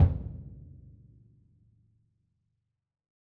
BDrumNewhit_v5_rr1_Sum.wav